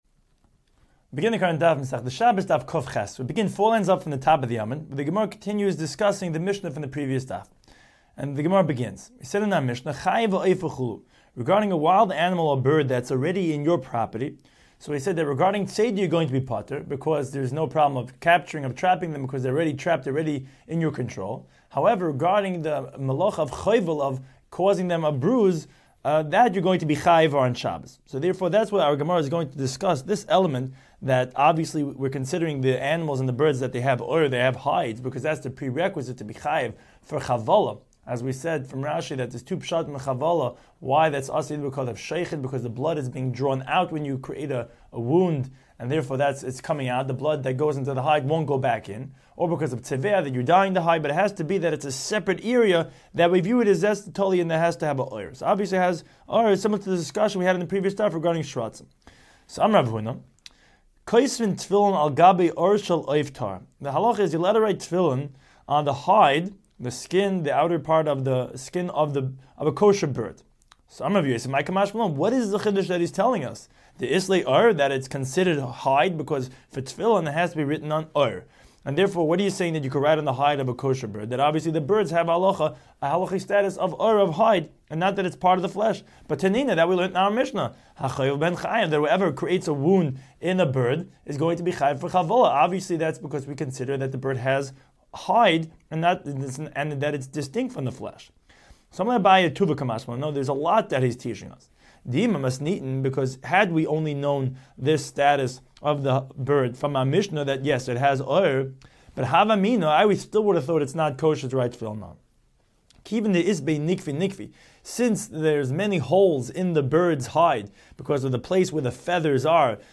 Daf Hachaim Shiur for Shabbos 108